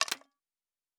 Fantasy Interface Sounds
Weapon UI 07.wav